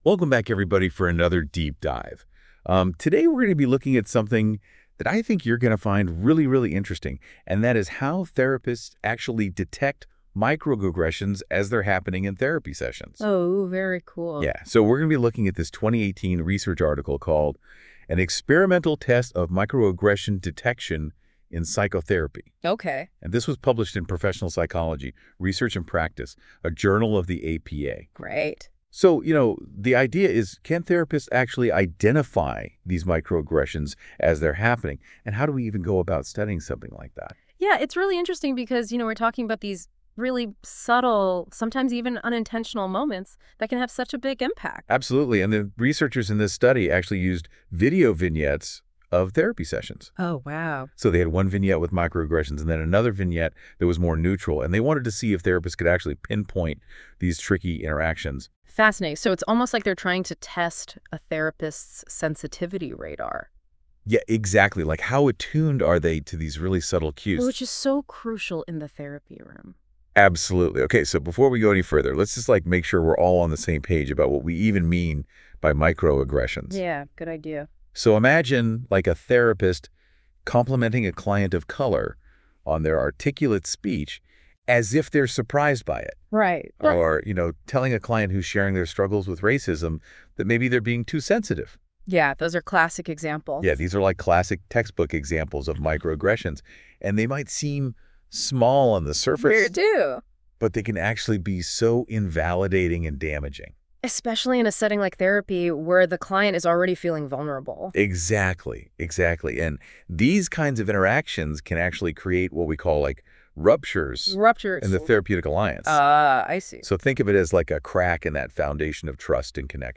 This podcast was generated by Notebook LM and reviewed by our team, please listen with discretion.